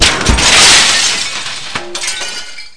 shatters.mp3